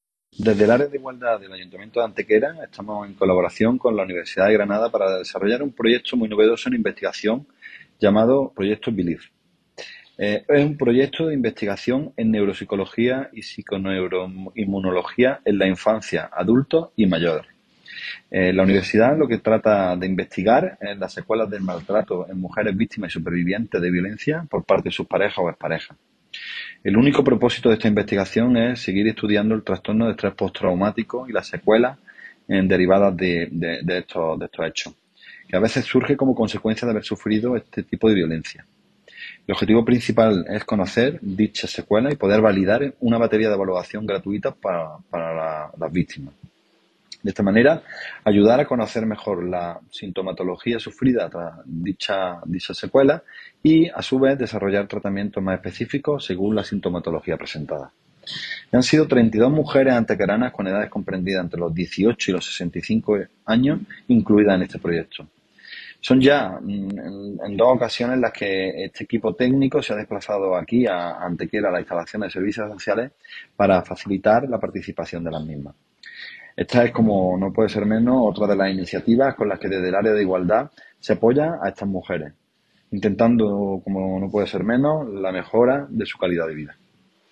El teniente de alcalde delegado de Programas Sociales e Igualdad, Alberto Arana, informa que el Ayuntamiento de Antequera se encuentra en la actualidad colaborando con la Universidad de Granada en el desarrollo de un novedoso proyecto de investigación que trata de abordar e identificar las secuelas del maltrato a mujeres que han sido cvíctimas de violencia de género por parte de sus parejas o ex parejas.
Cortes de voz